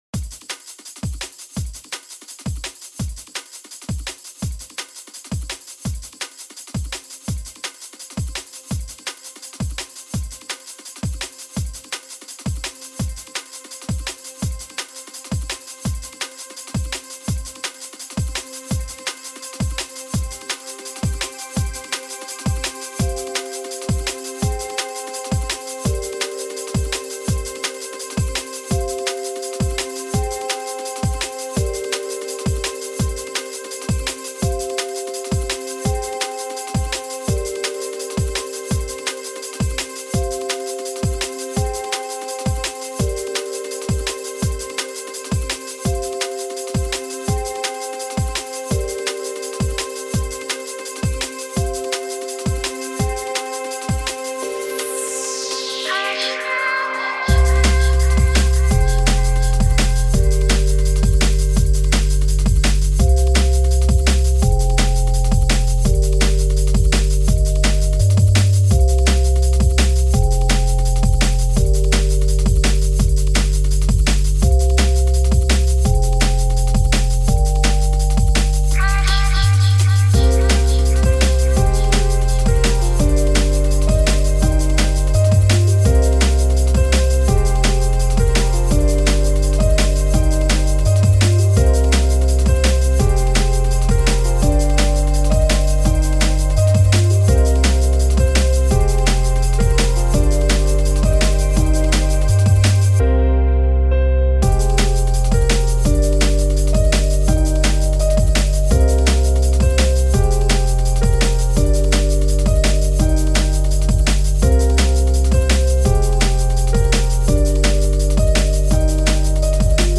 jazzy piano dnb and stuff
genre:dnb